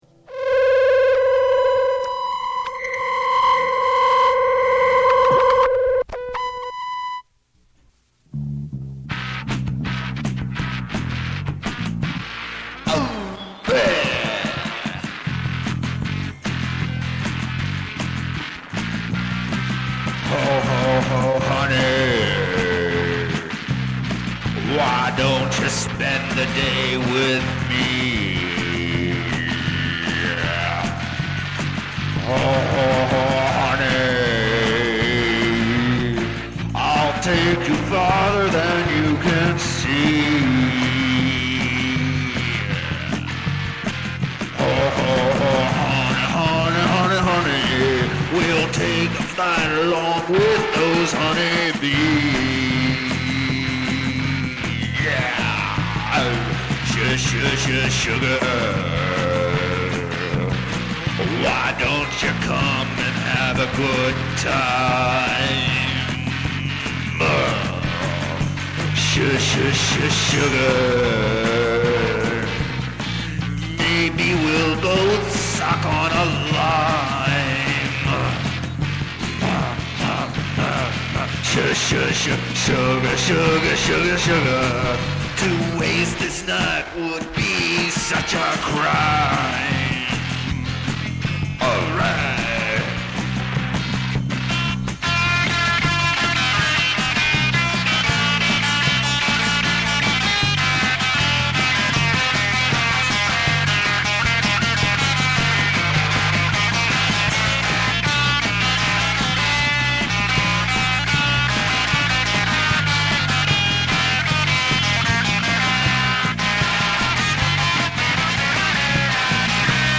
is the last song I wrote and recorded on my four-track tape recorder in my college dorm room in 1974
playing cymbal and the final guitar solo
The drum set consisted of my mattress, a padded chair with aluminum foil taped to the seat, and the aforementioned cymbal.  I produced the bass part by playing the low strings of my guitar with my tape recorder running at 2x the normal speed, so that the guitar part would be an octave lower upon playback.